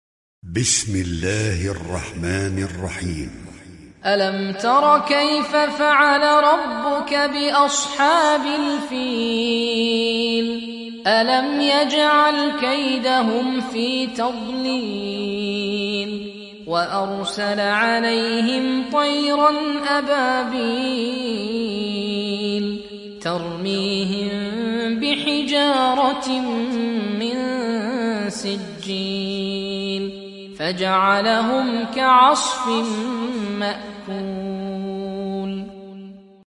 برواية حفص عن عاصم